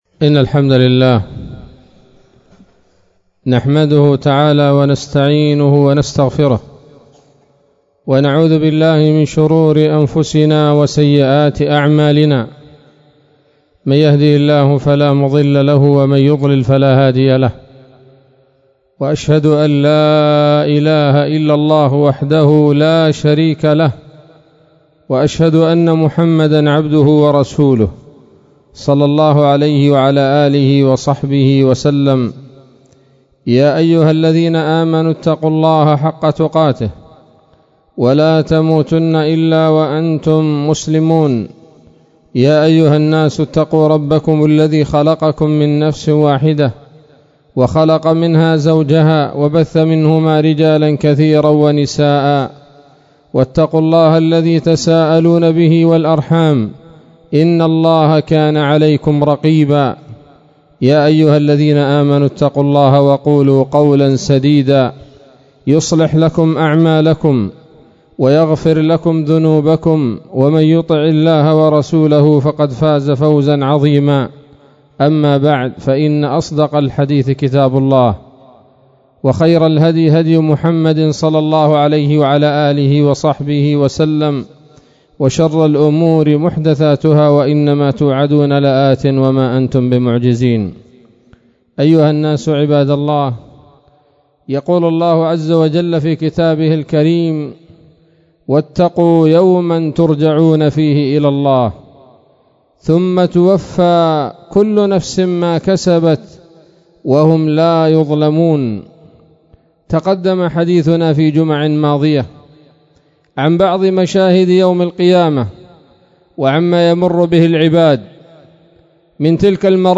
خطبة جمعة بعنوان: (( عقوبات العصاة في العرصات )) 30 من شهر جمادى الآخرة 1442 هـ